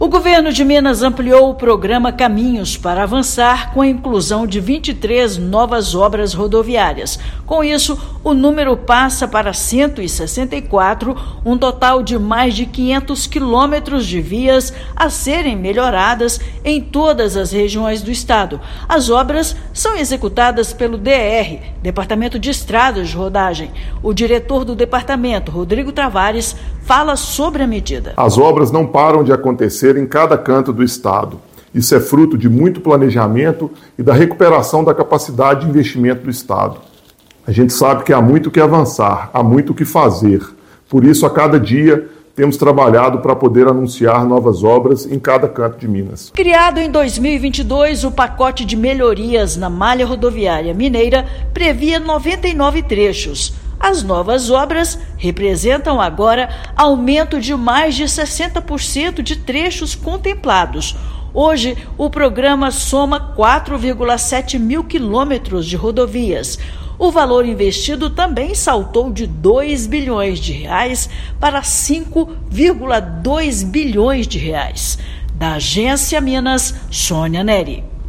[RÁDIO] Governo de Minas amplia programa Caminhos Pra Avançar com a inclusão de mais 23 obras rodoviárias
Mais de 500 quilômetros de rodovias recuperados vão melhorar a mobilidade e impulsionar o desenvolvimento regional. Ouça matéria de rádio.